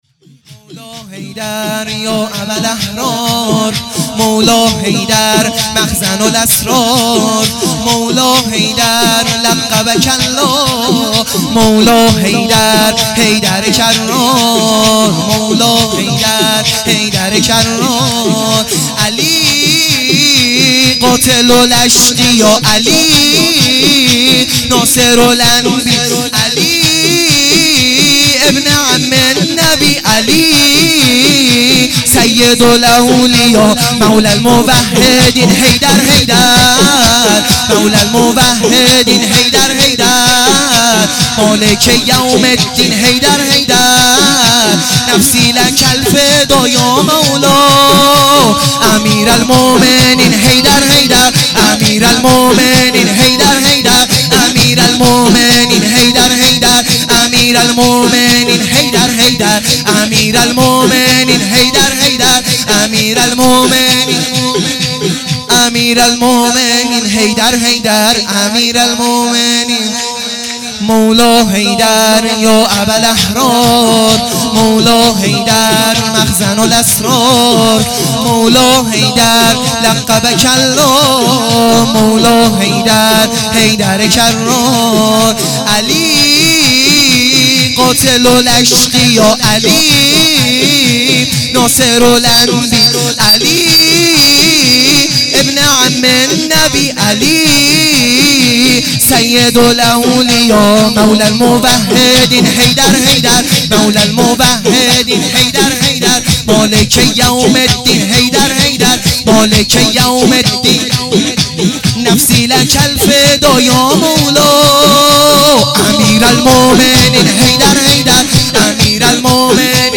سرود | مولا حیدر یا ابالاحرار